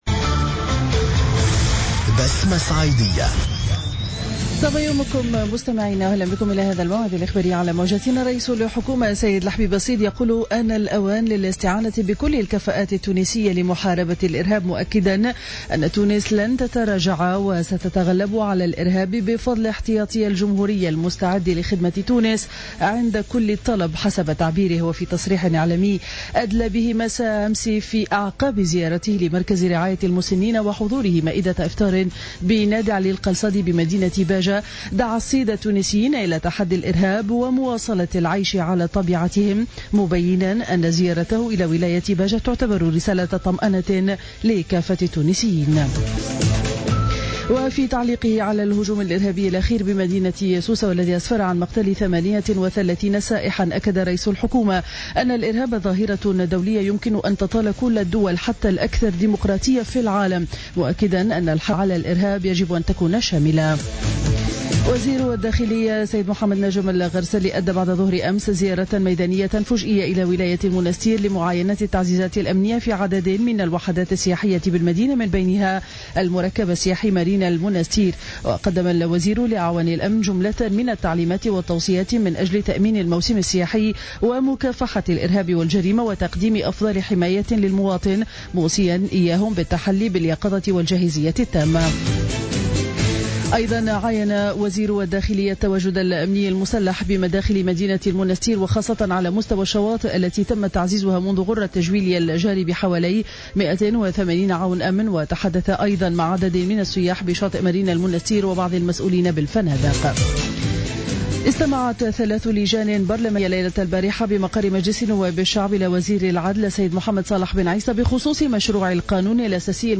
نشرة أخبار السابعة صباحا ليوم الخميس 9 جويلية 2015